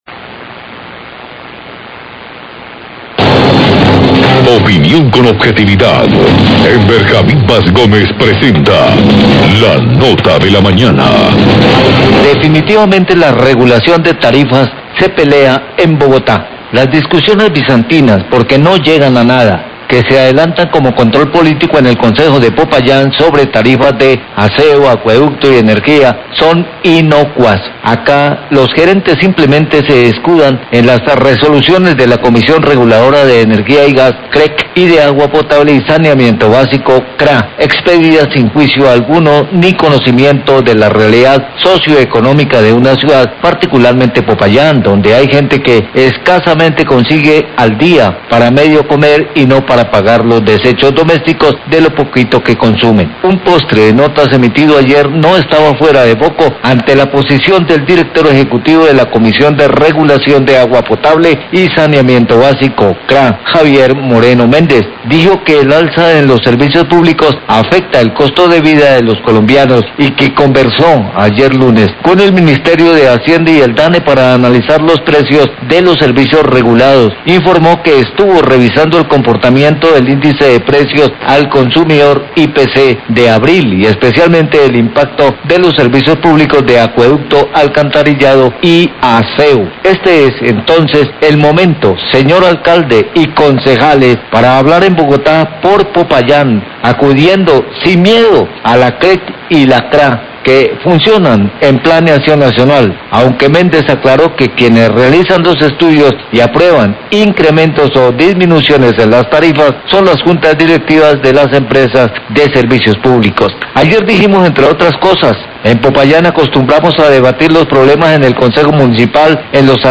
Radio
editorial